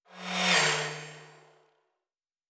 pgs/Assets/Audio/Sci-Fi Sounds/Movement/Fly By 02_3.wav at 7452e70b8c5ad2f7daae623e1a952eb18c9caab4
Fly By 02_3.wav